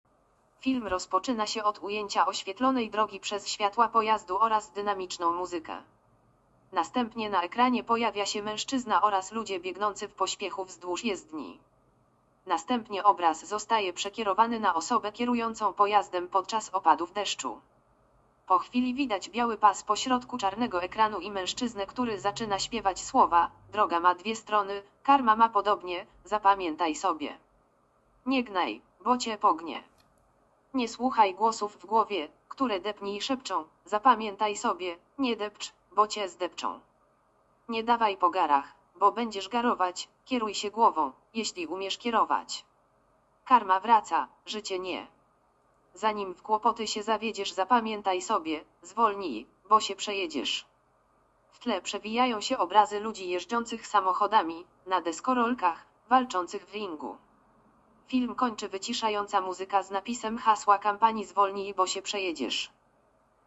Nagranie audio Audiodeskrypcja "Spotu zwolnij bo sięprzejedziesz"